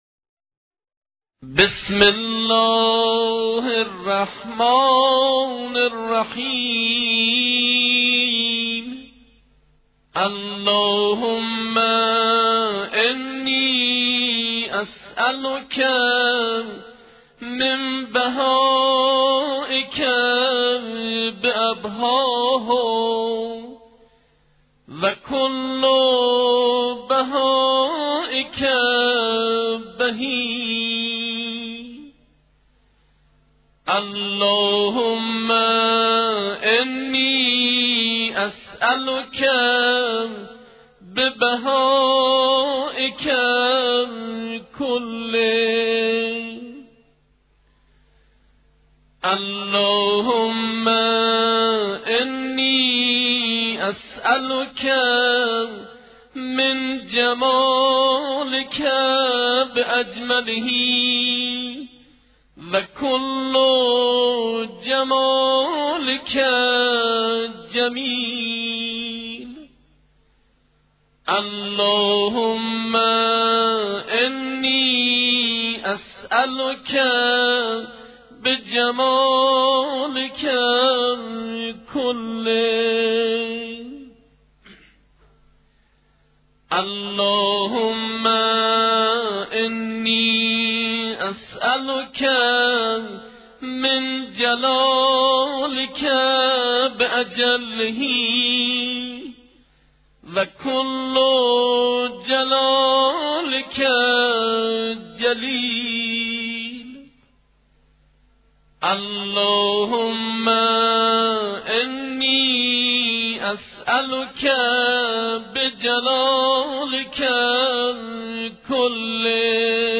صدای دعای سحر از خونه همسایه میاد.
دعای-سحر.mp3